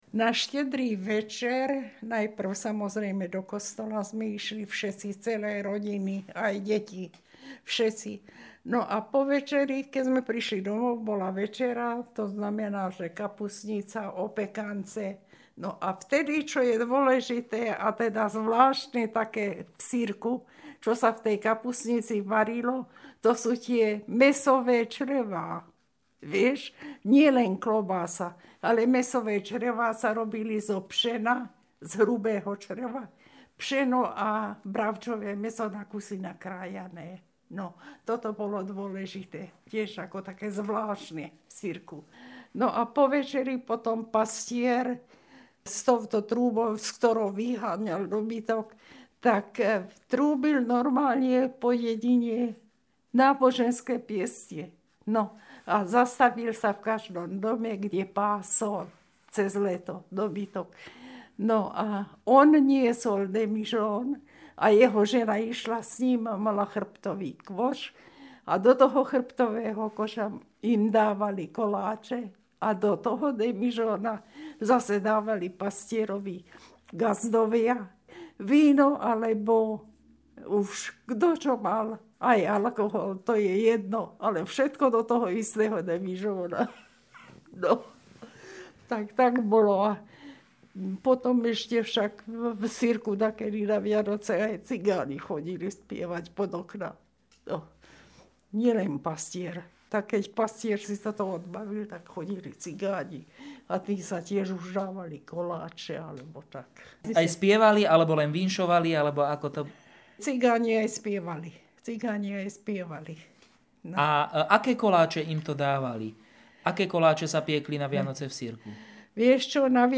Municipality of originSirk, okres Revúca
Place of capture Sirk